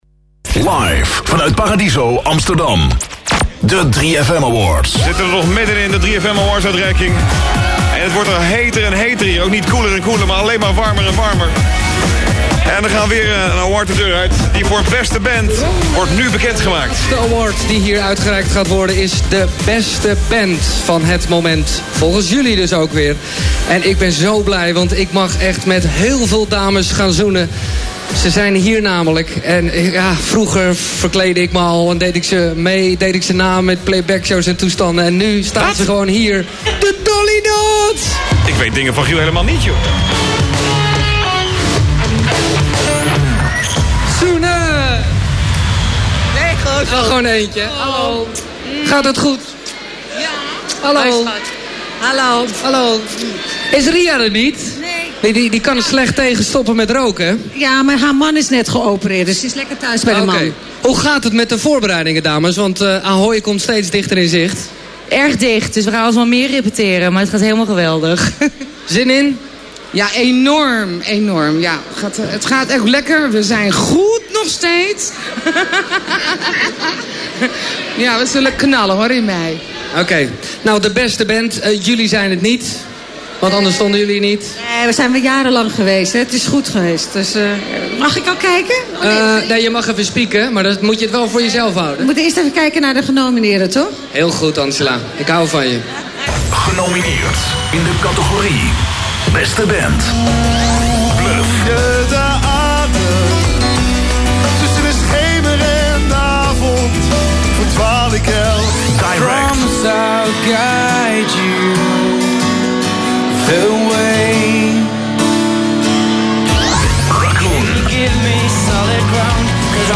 • Uitreiking 3fm Award door de Dolly Dots